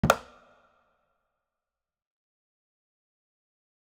UI sound trad hover 1.wav